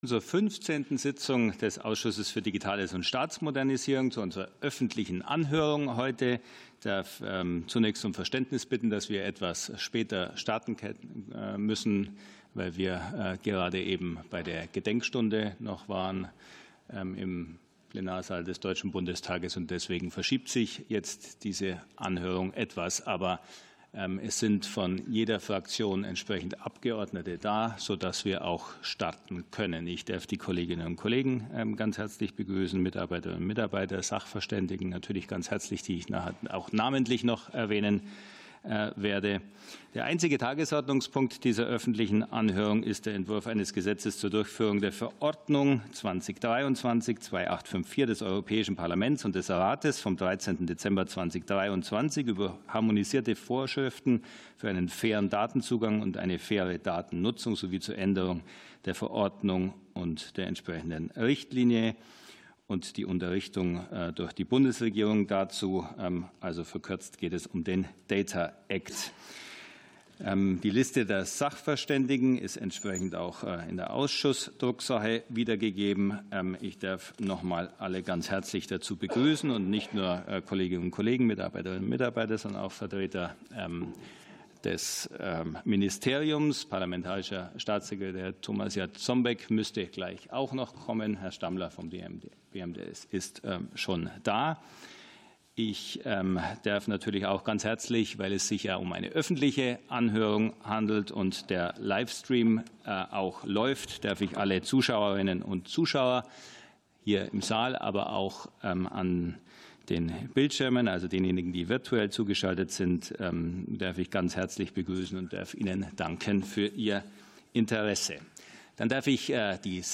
Anhörung des Ausschusses für Digitales und Staatsmodernisierung